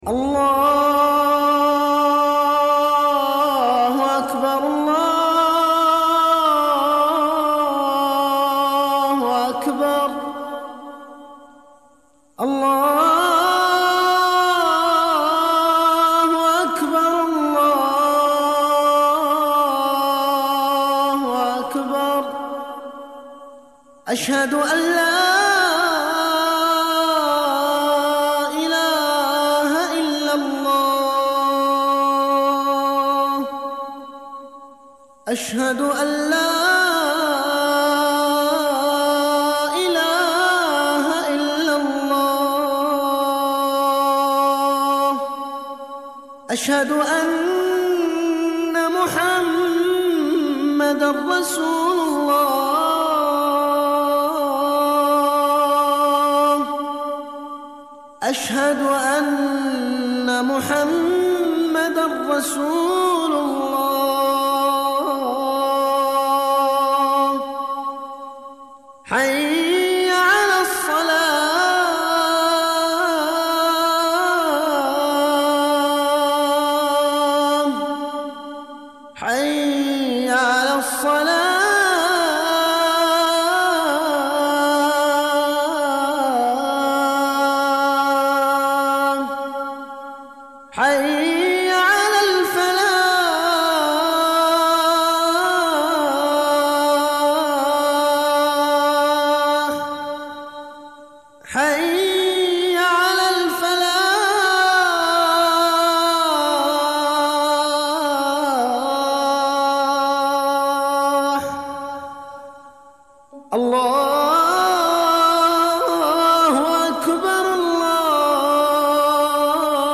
المكتبة الصوتية روائع الآذان المادة آذان